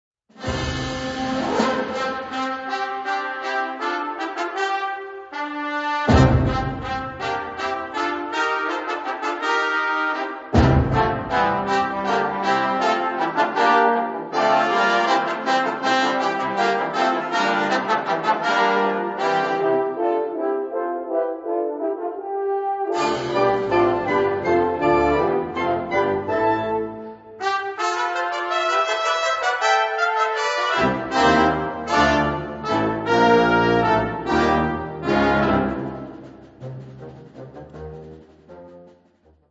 2 Adagio 5:13